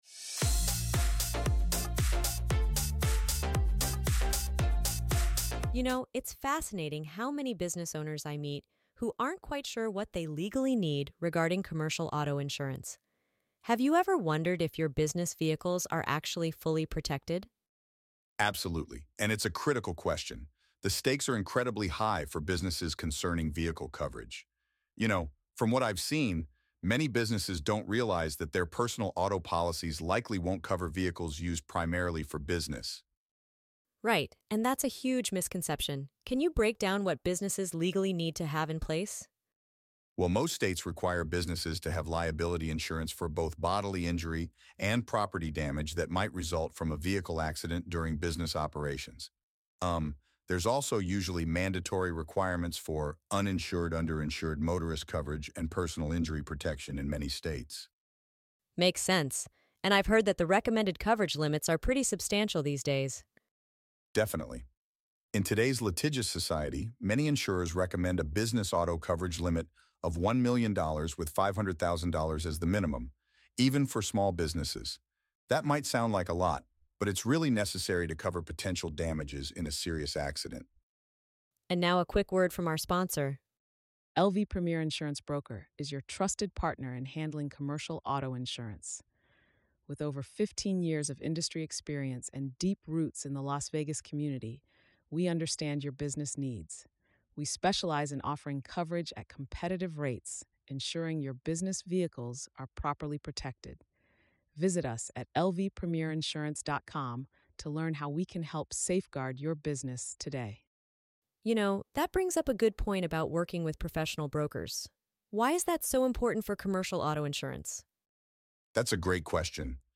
If you want to know what the requirements for commercial auto insurance in Las Vegas are, these two expert brokers discuss legal coverage requirements and the benefits of a well-selected policy.